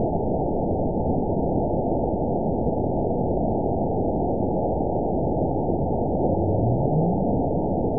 event 916826 date 02/09/23 time 18:09:53 GMT (2 years, 3 months ago) score 9.61 location TSS-AB05 detected by nrw target species NRW annotations +NRW Spectrogram: Frequency (kHz) vs. Time (s) audio not available .wav